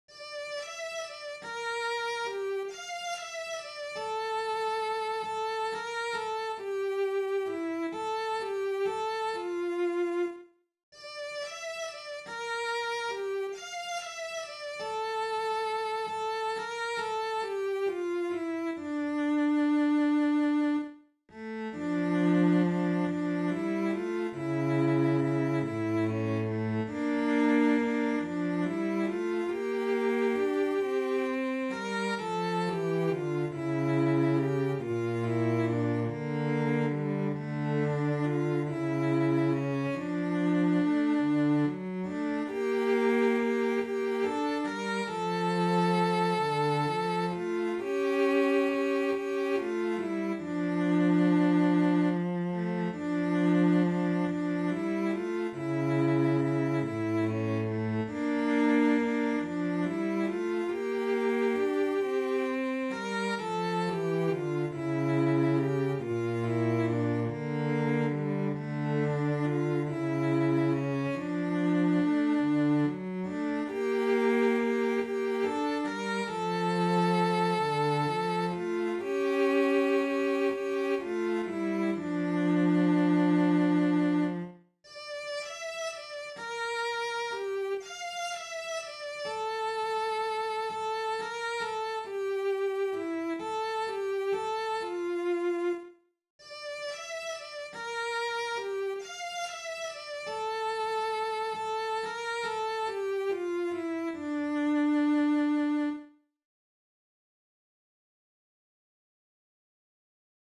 Kuin-metsakyyhkynen-eramaassa-sellot.mp3